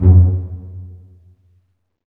STR PIZZ.04L.wav